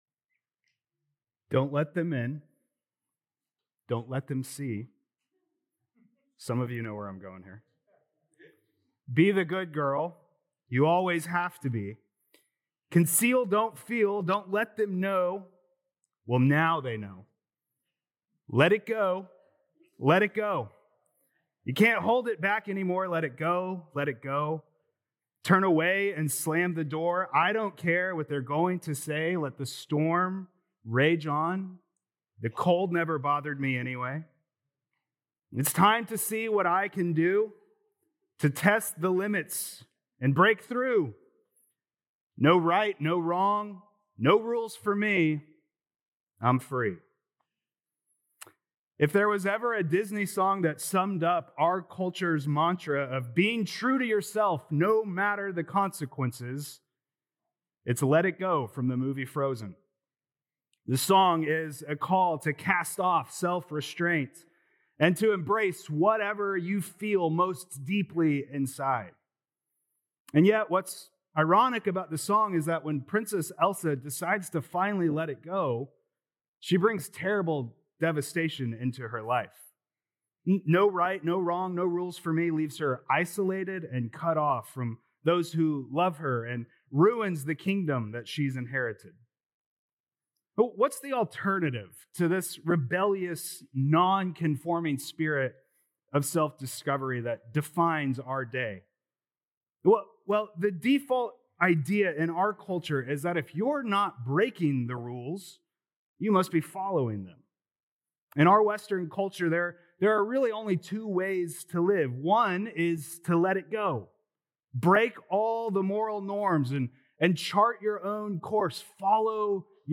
Mar 8th Sermon